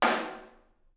R_B Snare 05 - Room.wav